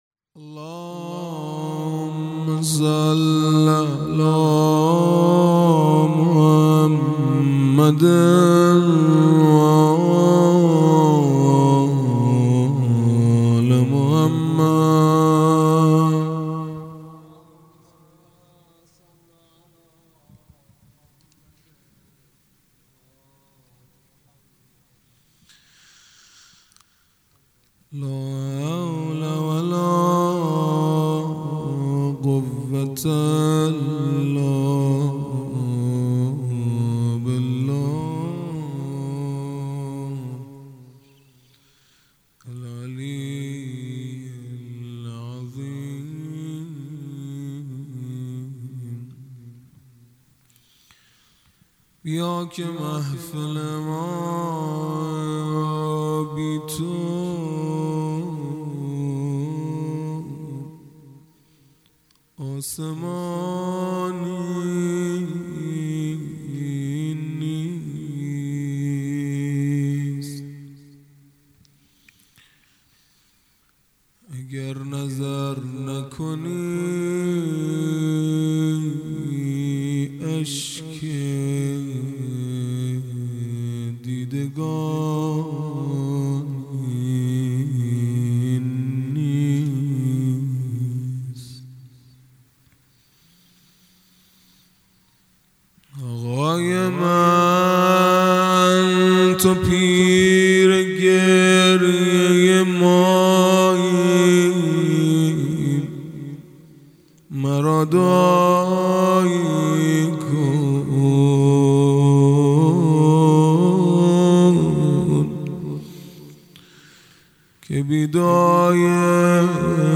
خیمه گاه - هیئت بچه های فاطمه (س) - زیارت عاشورا | دوشنبه ۱۷ مرداد ماه ۱۴۰۱